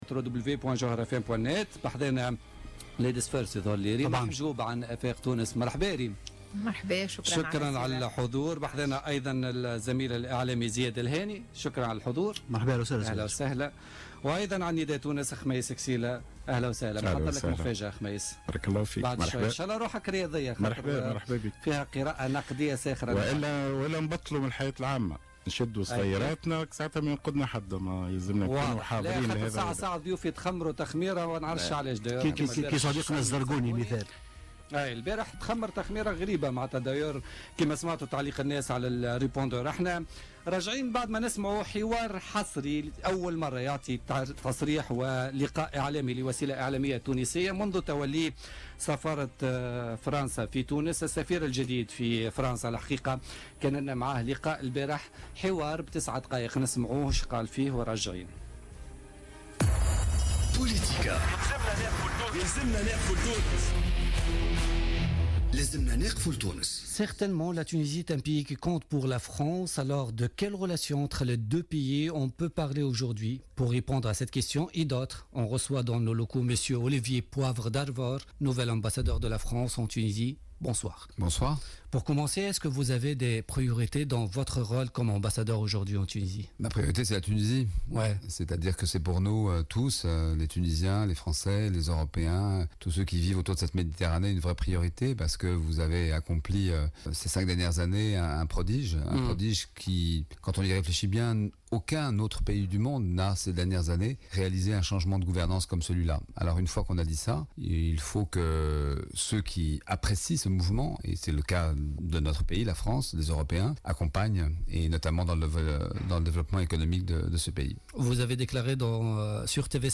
L'ambassadeur de France en Tunisie, Olivier Poivre d'Arvor était l'invité de l'émission Politica de ce mardi 11 octobre 2016 sur Jawhara FM.